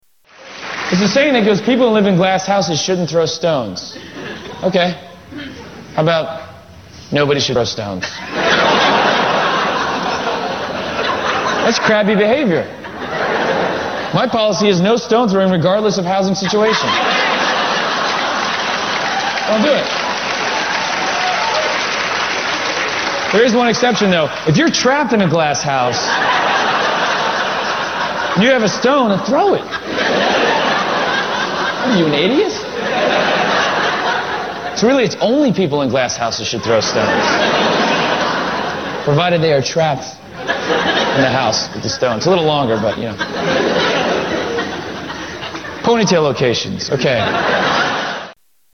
Tags: Demetri Martin Demetri Martin clips Demetri Martin jokes Demetri Martin audio Comedian